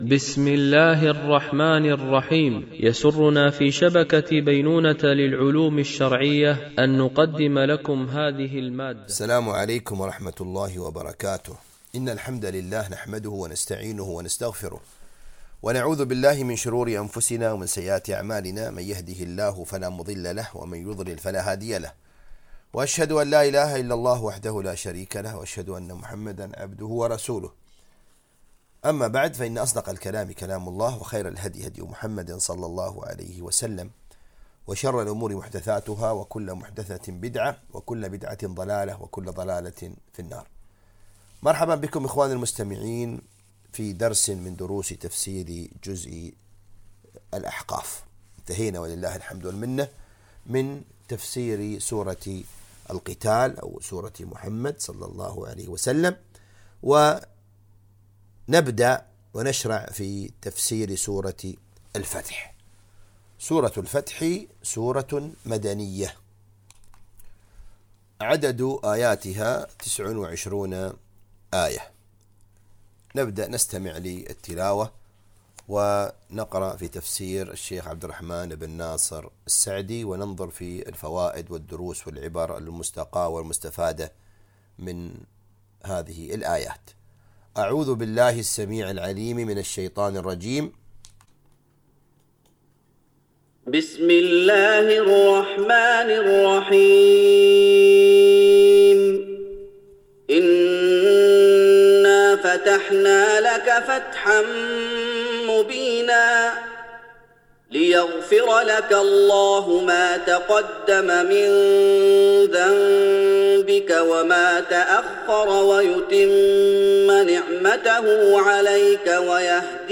تفسير جزء الذاريات والأحقاف ـ الدرس 10 ( سورة الفتح )